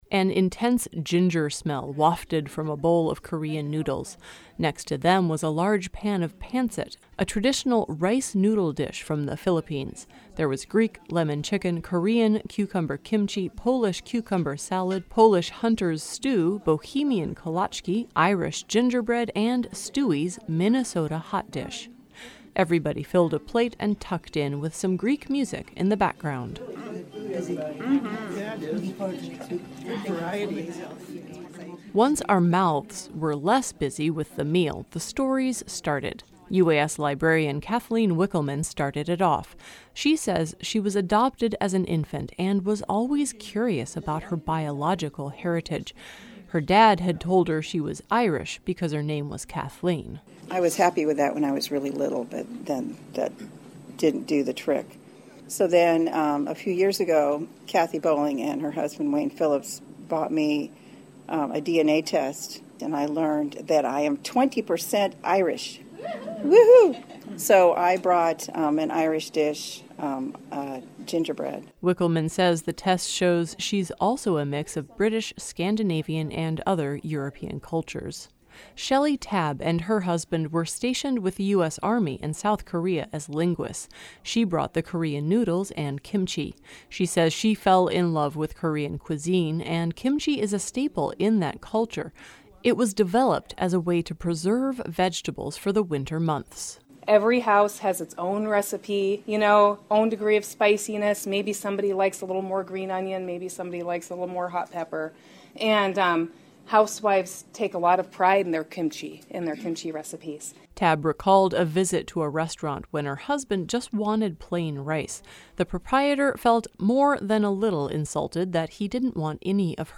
Everybody filled a plate, and tucked in, with some Greek music in the background.